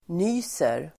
Ladda ner uttalet
Uttal: [n'y:ser]